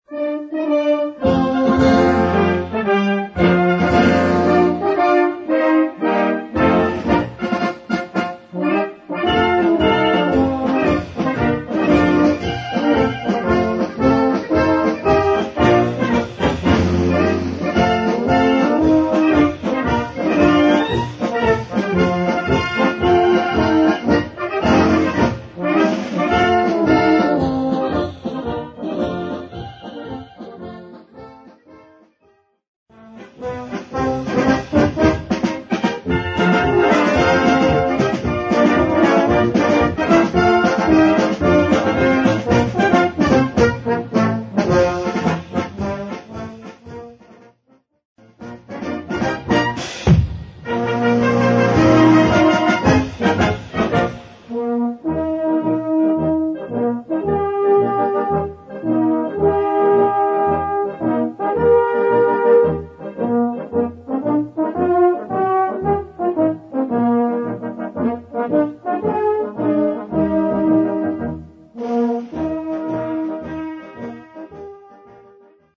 Kategorie Blasorchester/HaFaBra
Unterkategorie Strassenmarsch
Besetzung Ha (Blasorchester)